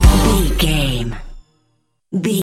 Uplifting
Aeolian/Minor
Fast
drum machine
synthesiser
electric piano